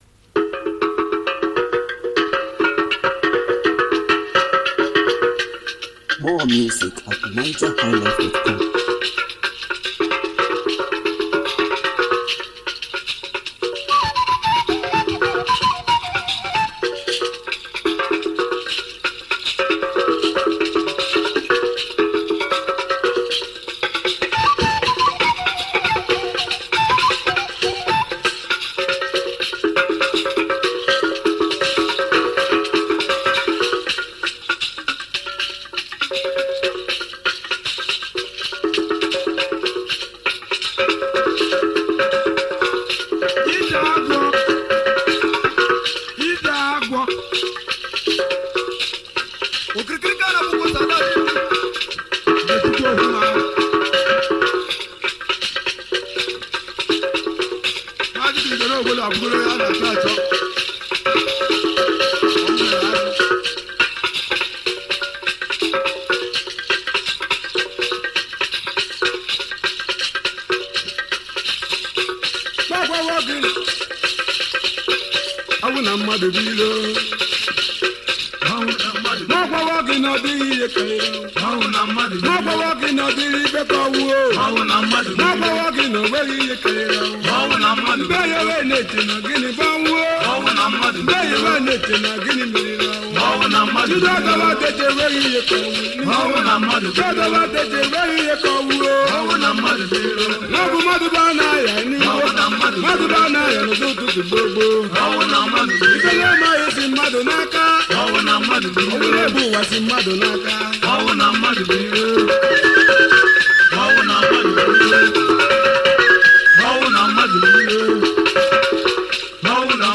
Home » Ogene